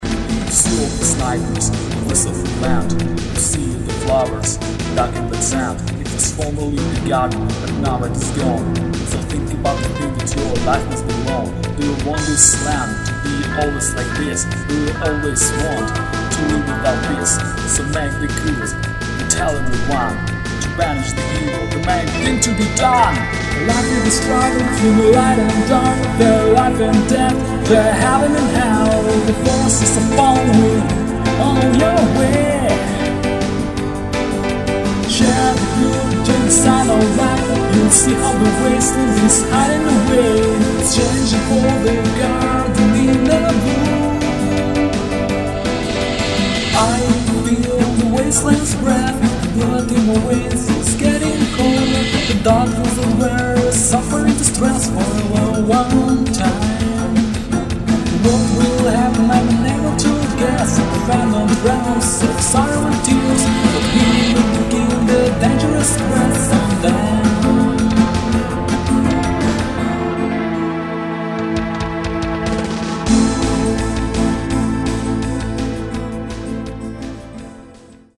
Песенные композиции: